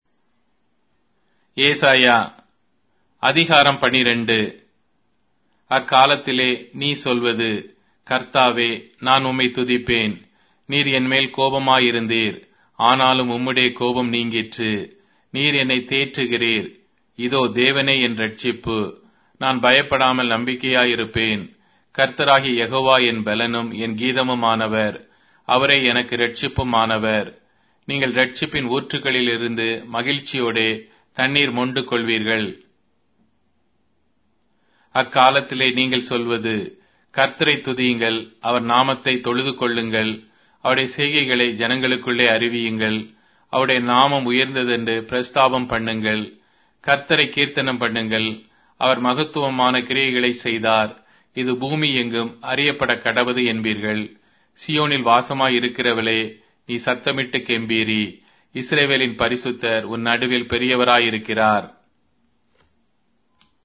Tamil Audio Bible - Isaiah 49 in Ervkn bible version